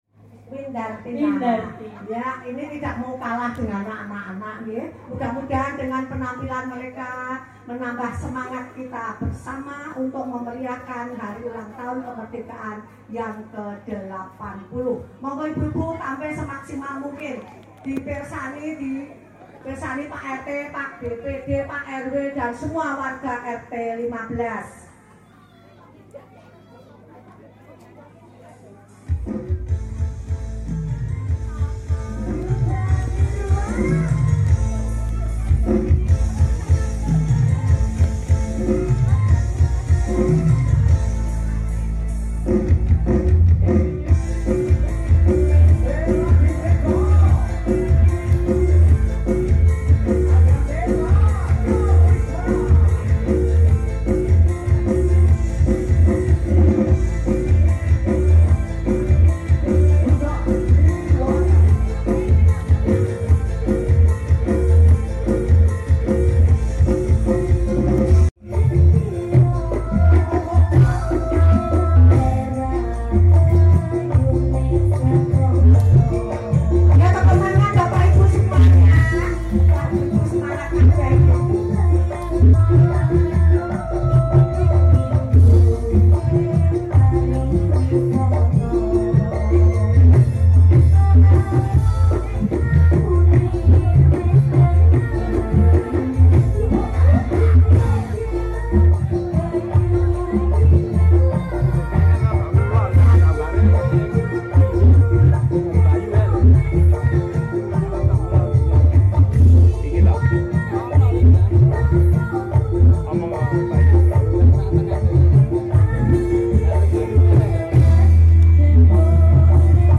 Penampilan Gerak dan Lagu Perahu sound effects free download
Perahu Layar dari ibu ibu warga